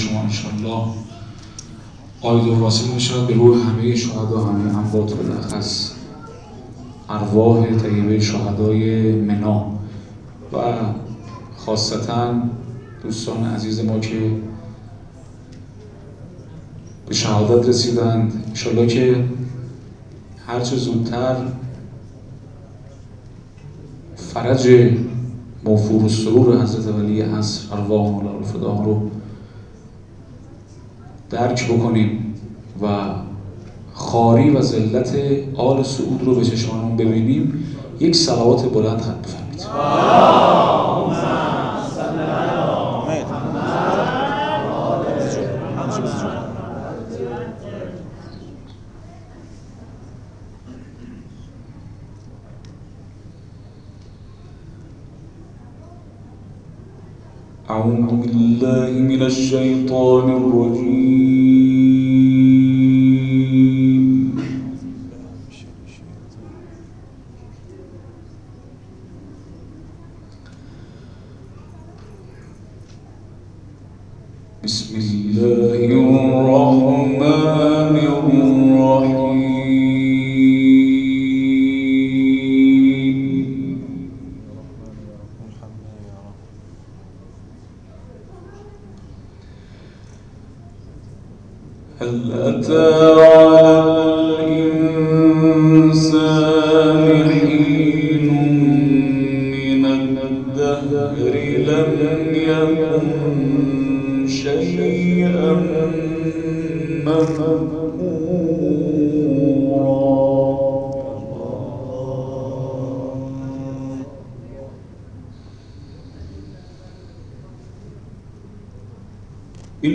گروه فعالیت‌های قرآنی: کرسی تلاوت قرآن، شب گذشته 21 خردادماه در مسجد حضرت ابوالفضل(ع) عظیم‌آباد برگزار شد.